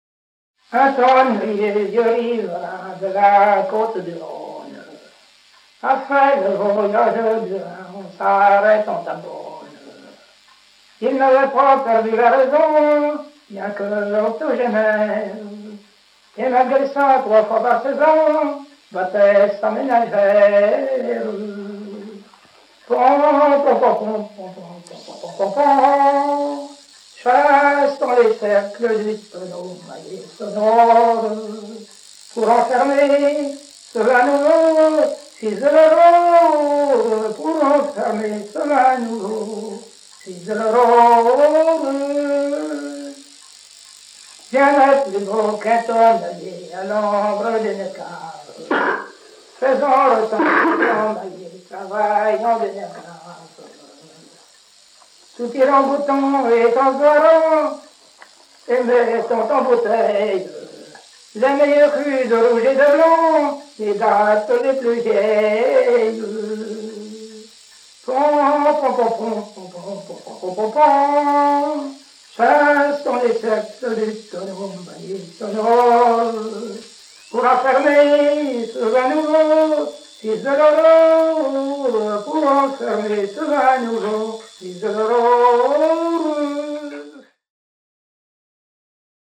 chanteur(s), chant, chanson, chansonnette
Plaine vendéenne
Genre strophique